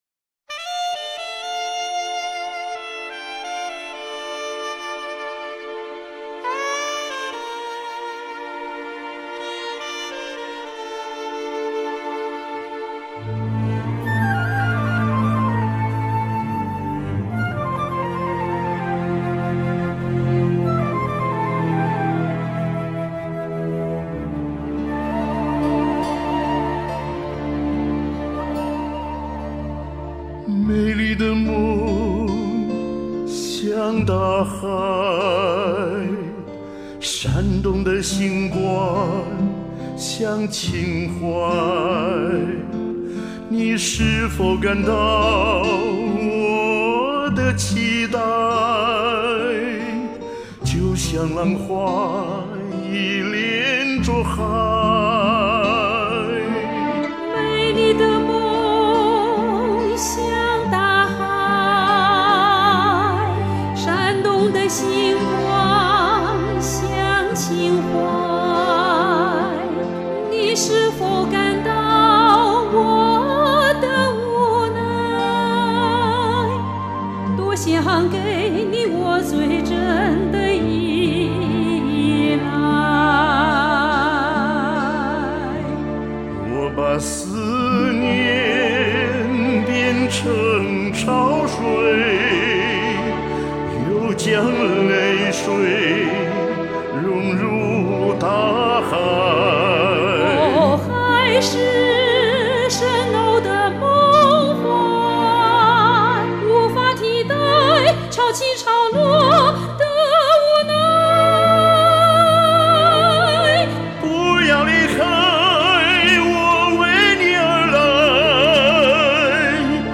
合作的很棒，抒情如傾訴，高潮迭起，和聲更突出兩把好聲音！
二人對唱，氣場強大，很有歌劇的味道👍👏👏
兩把好聲音殿堂級演繹，深沉厚重，大氣恢弘，聲情並茂，震撼人心
充滿激情的一首歌，二位唱得簡直了！